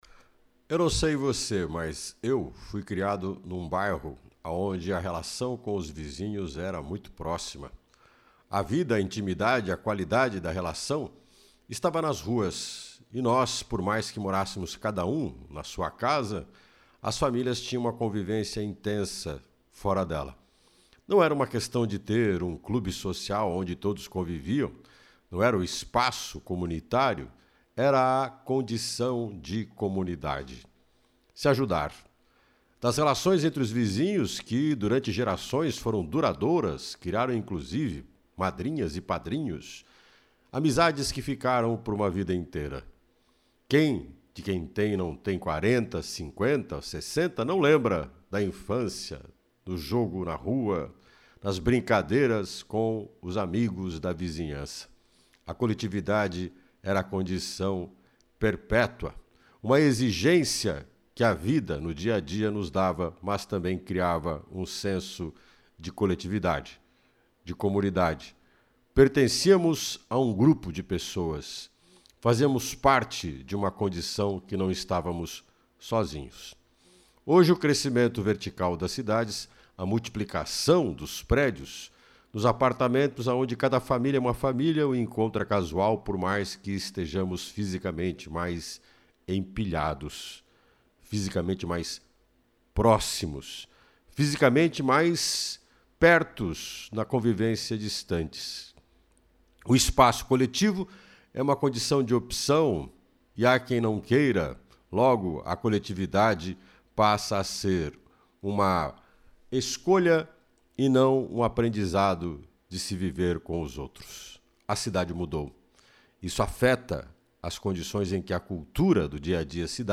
Opinião